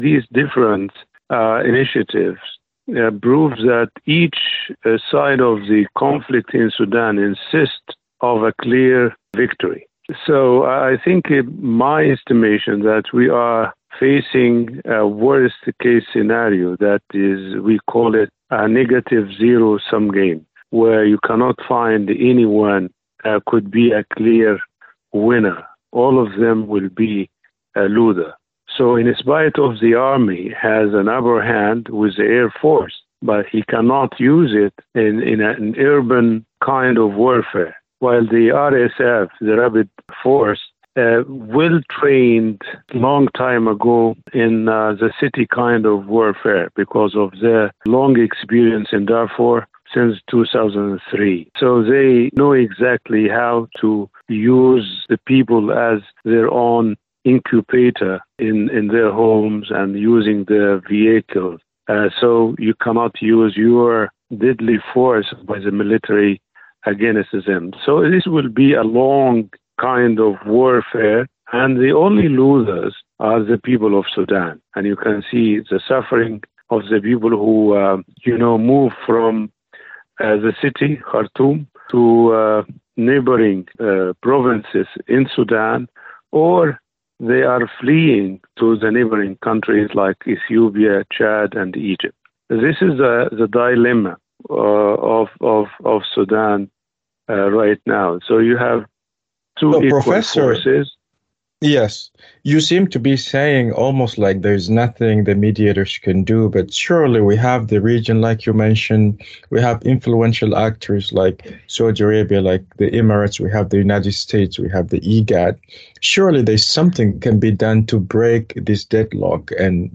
Analysis: Sudan's Escalating Violence Indicates Prolonged Conflict Ahead [4:15]